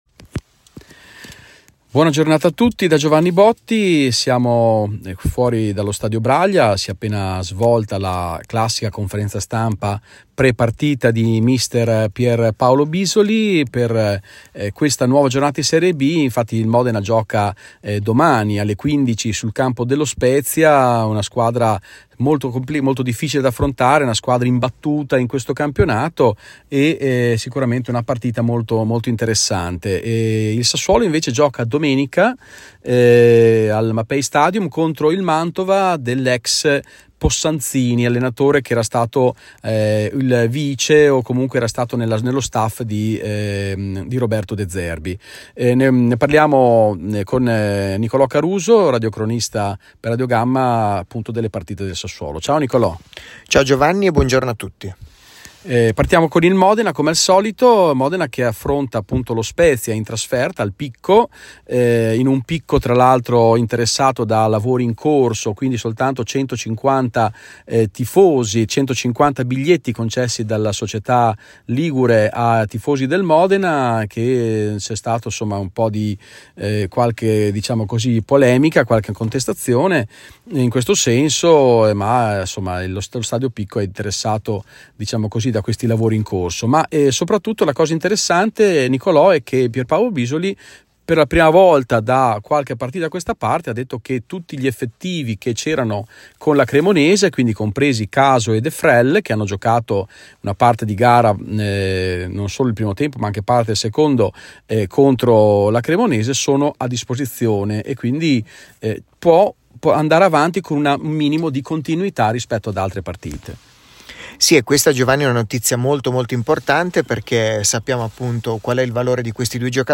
La presentazione audio delle partite del weekend delle squadre modenesi di serie B, Spezia-Modena e Sassuolo-Mantova.